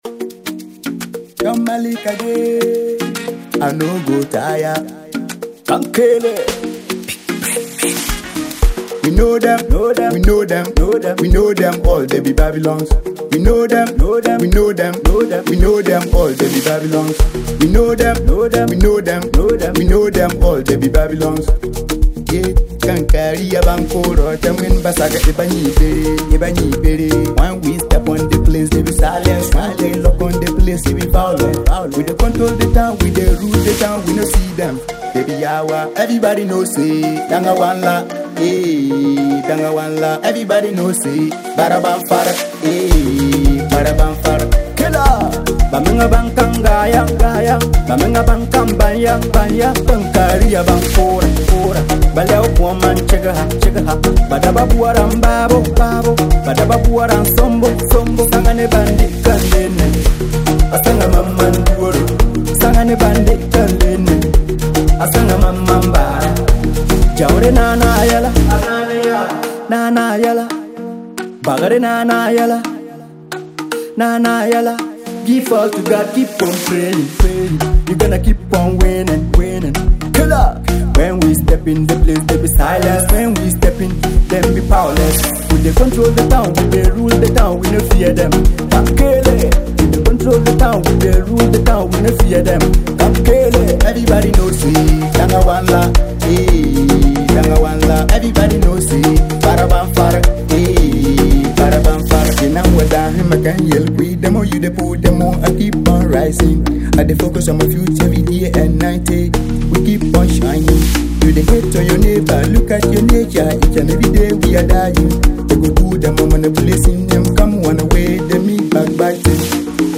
Genre: Afrobeats